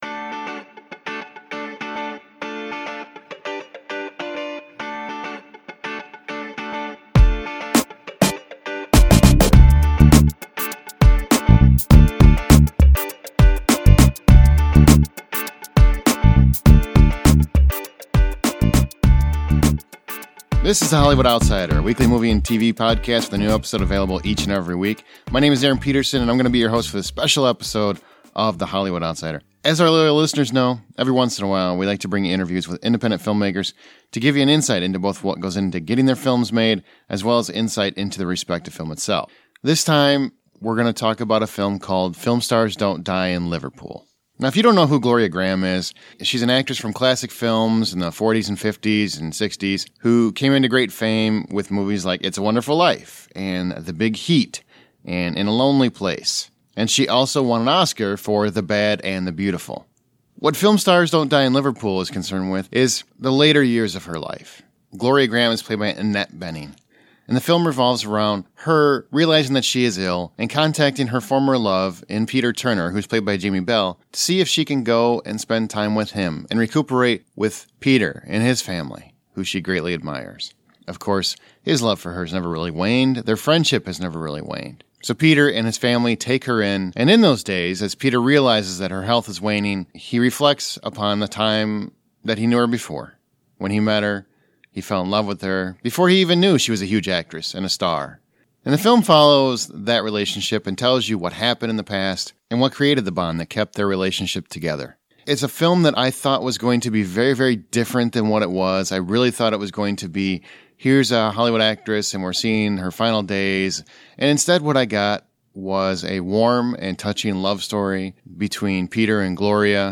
Interview with Director Paul McGuigan | Film Stars Don't Die In Liverpool
Interview-with-Director-Paul-McGuigan-Film-Stars-Dont-Die-In-Liverpool.mp3